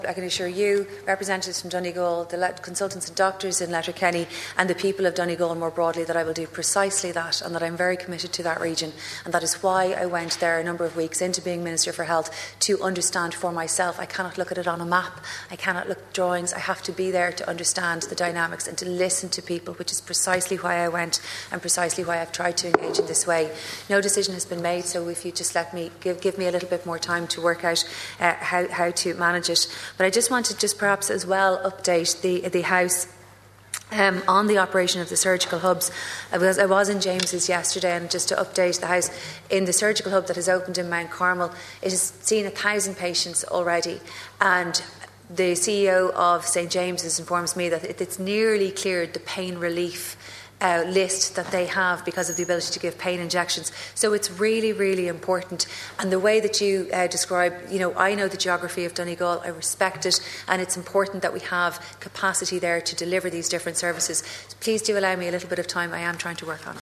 In response, the Minister appealed for time to given for her to reach a decision: